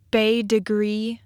But for how "Bete Grise" is 'officially' pronounced in Michigan, listen to the LARA¹ Pronunciation Guide - 'You Say it How in Michigan?
Kinda funny, they got the pronunciation right, but misspelled it!